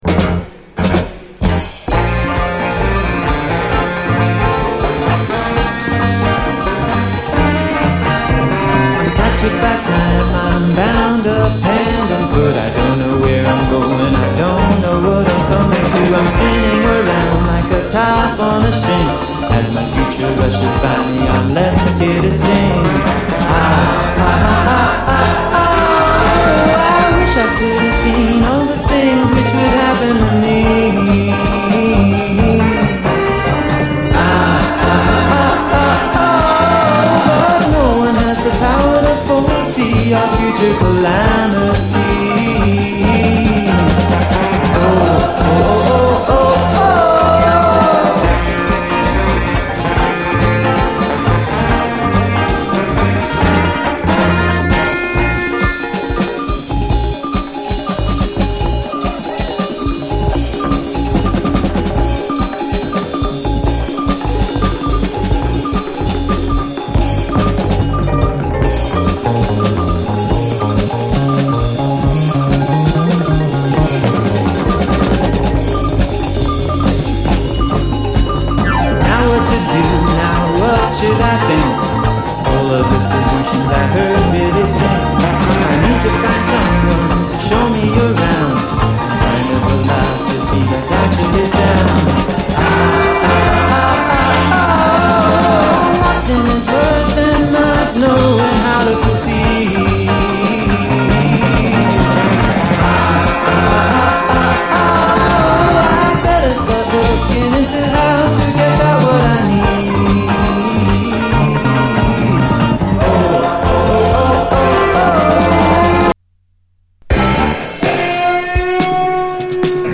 シンセ・サウンドが気持ちいいトロピカル・ラテン・ダンサー
バレアリック感溢れるネットリしたメロウ・グルーヴ